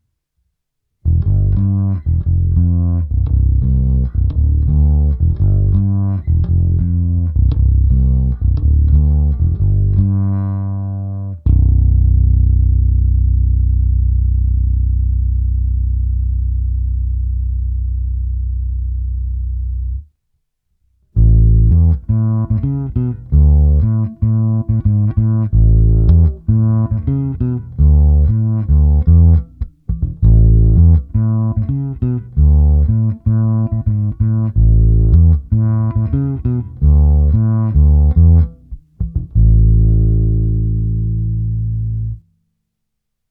Krkový snímač